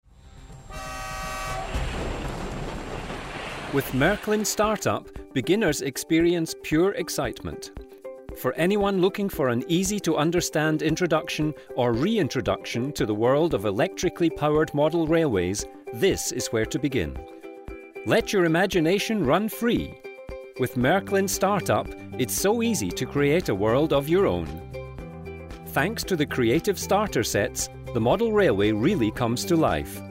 Englische Vertonung: